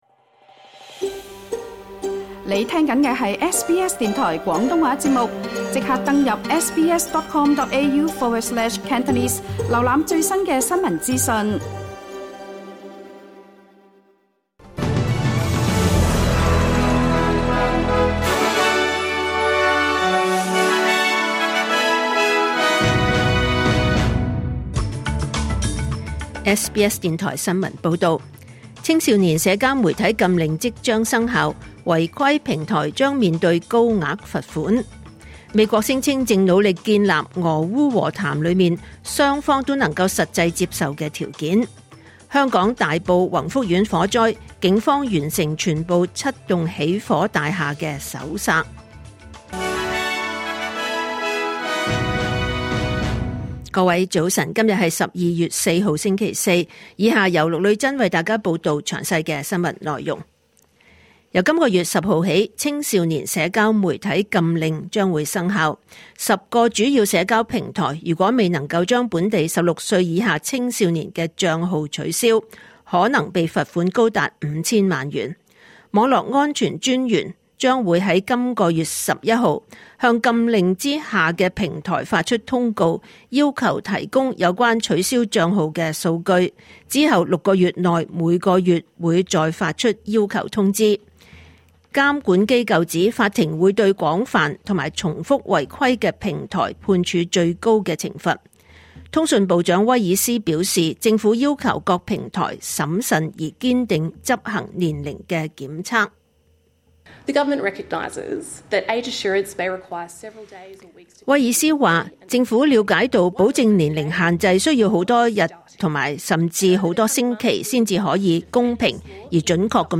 2025年12月4日，SBS廣東話節目九點半新聞報道。